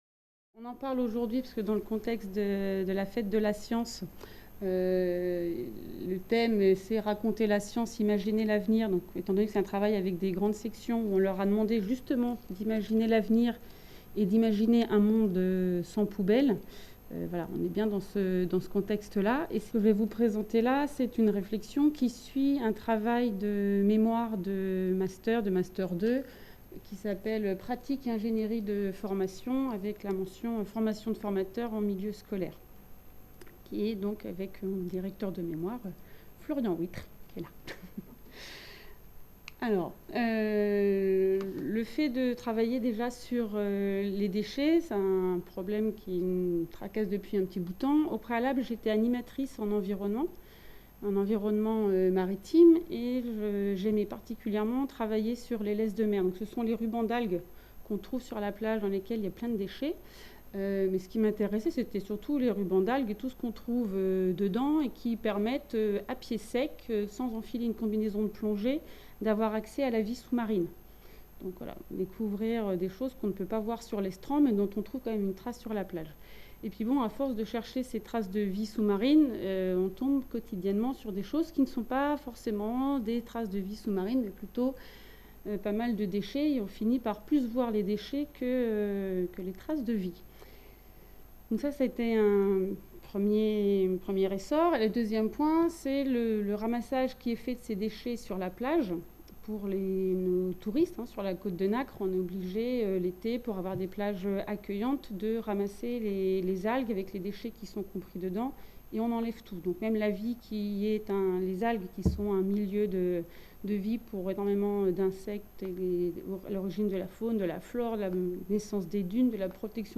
Cette communication a été filmée lors de la série d'événements organisé par la MRSH autour de la question des déchets, abordant les enjeux écologiques qui se posent à nos sociétés.